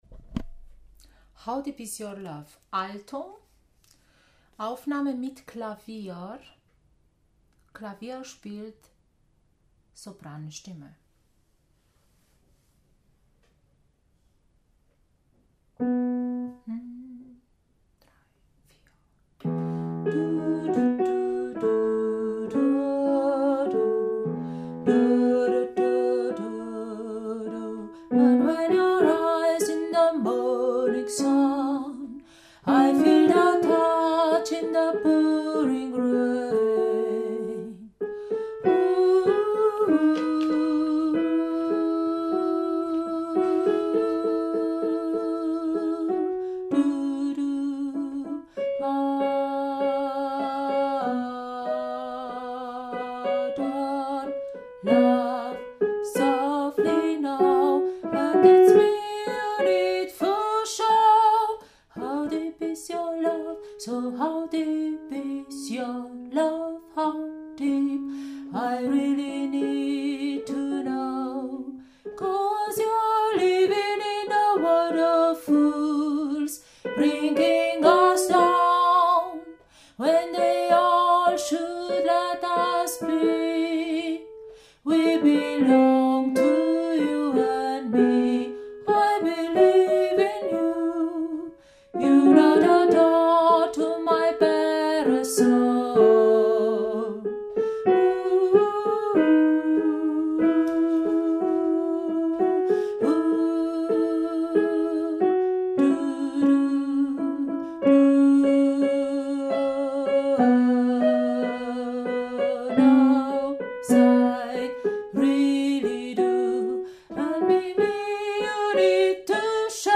How deep is your love – Alto (mit Klavierbegleitung Sopran)
How-deep-is-your-love-Alto-+SopranKlavier.mp3